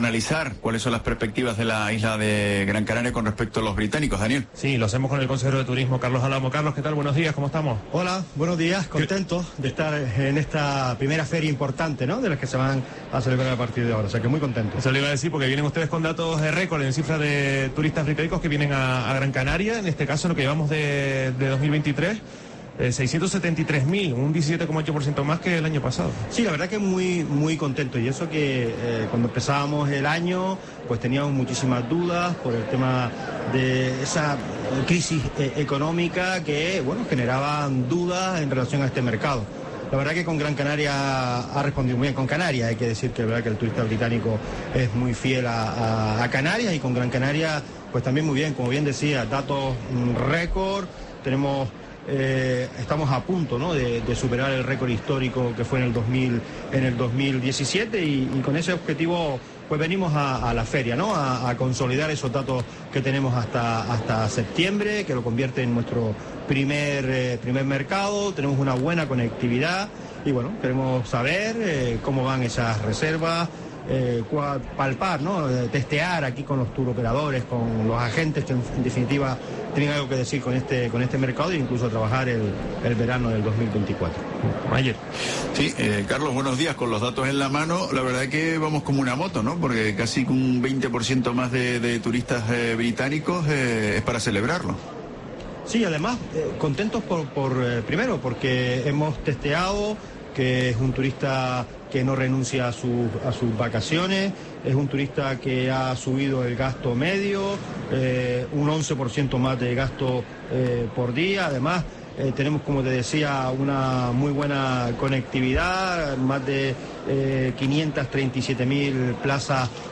Entrevista a Carlos Álamo, consejero de Turismo del Cabildo de Gran Canaria, en la WTM